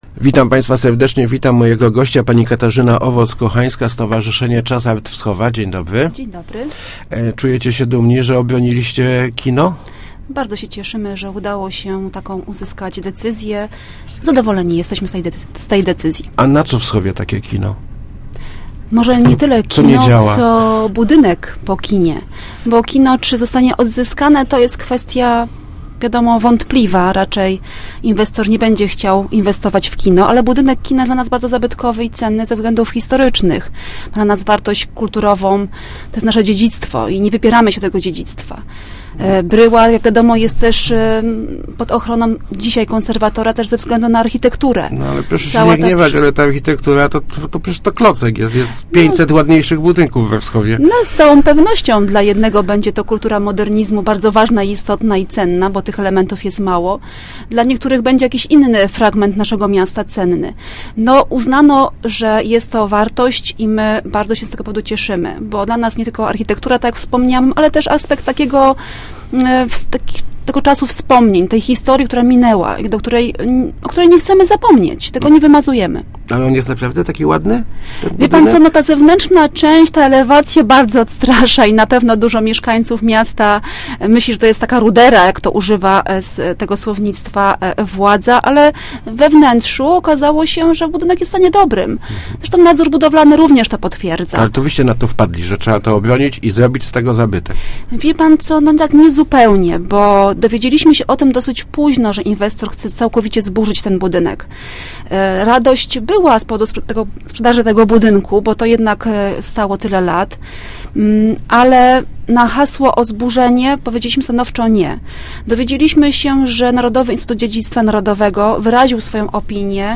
w Rozmowach Elki